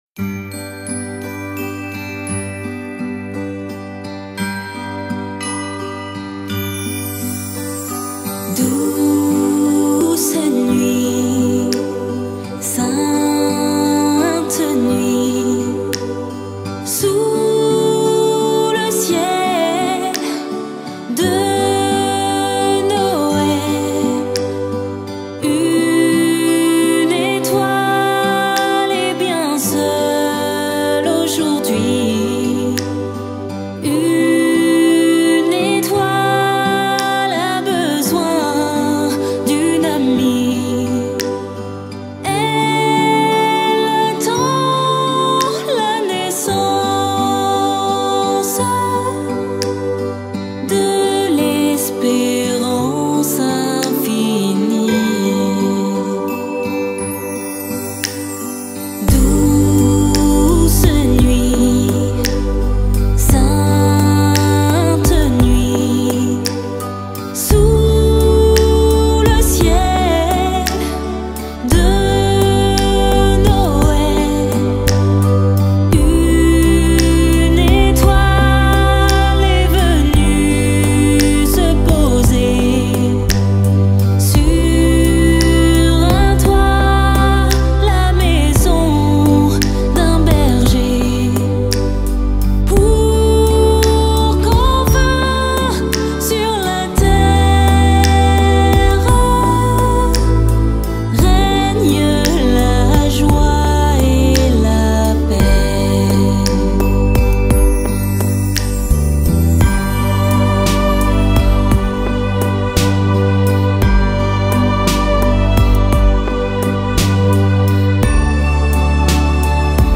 Célébration de Noël